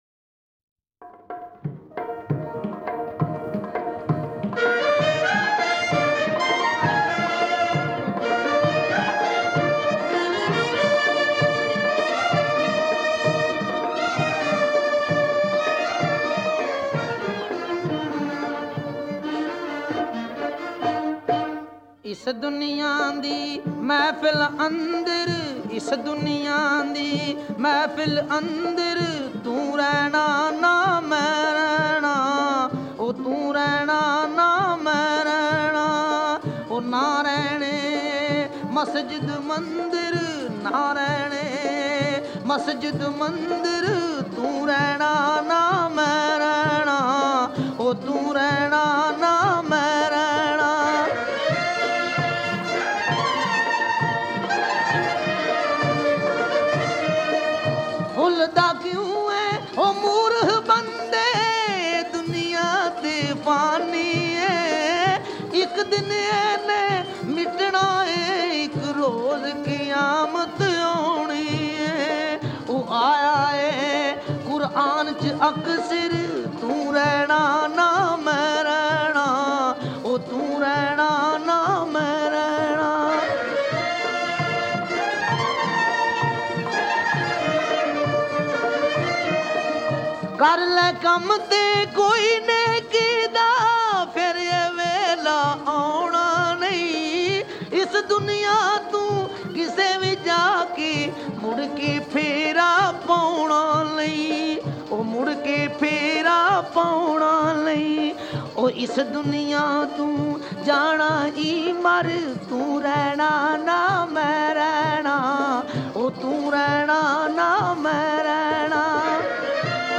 Arfana & Sufiana Kalam